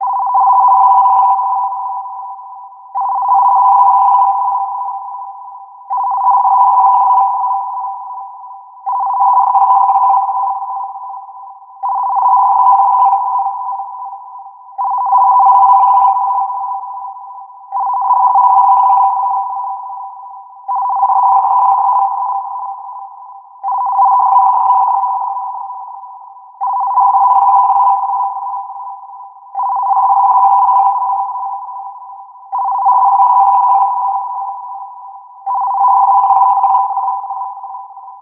音源はシンプルな固定電話の着信音ですがリフレインさせています。